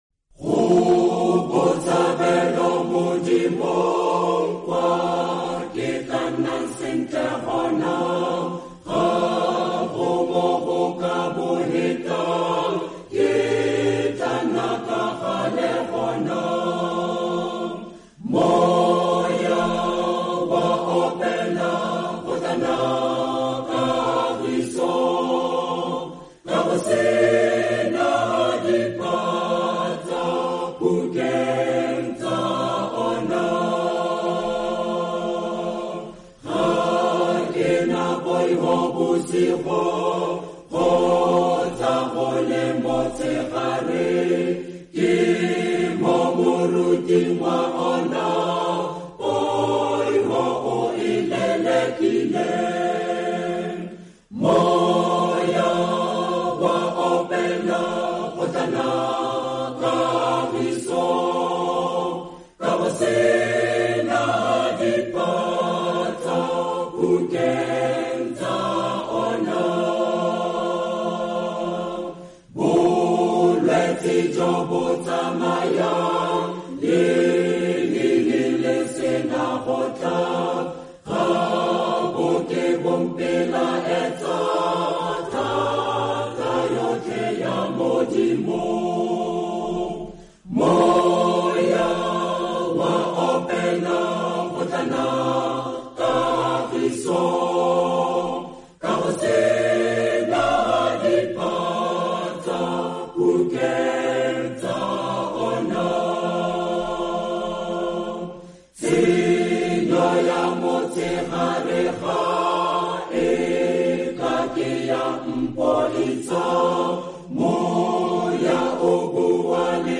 Go Botshabelo Modimong Hymn